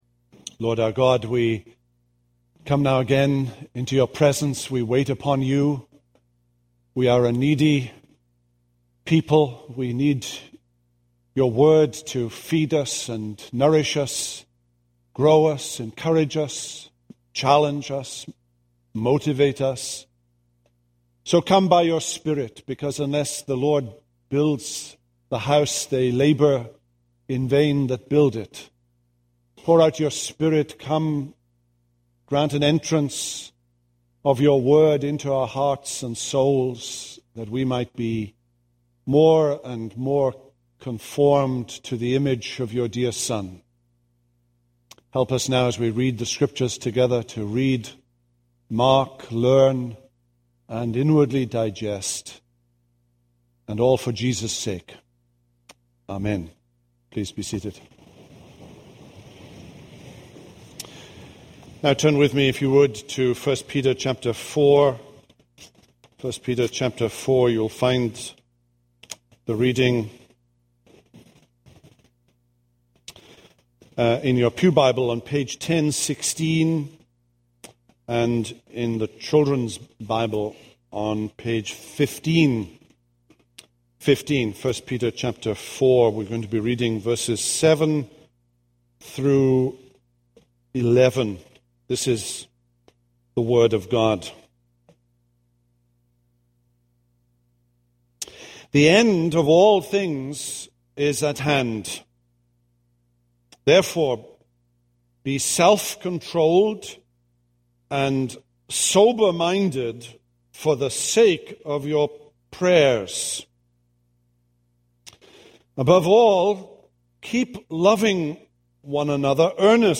This is a sermon on 1 Peter 4:7-11.